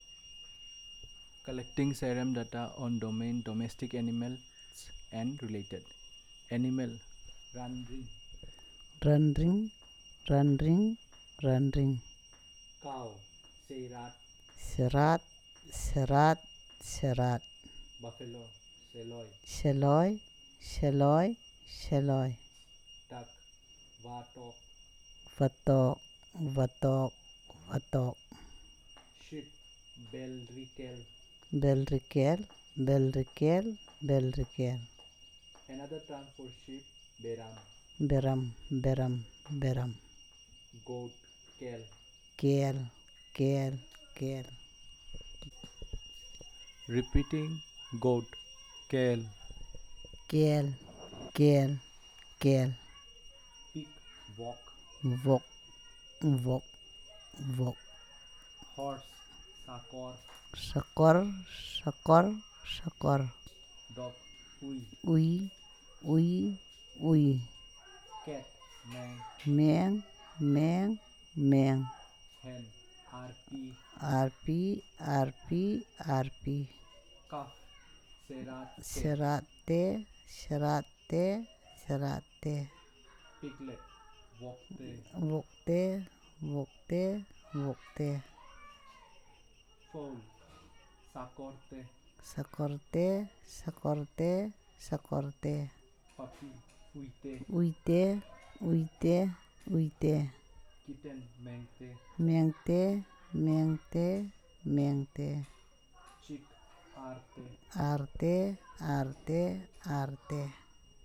dc.description.elicitationmethodInterview method
dc.type.discoursetypeElicitation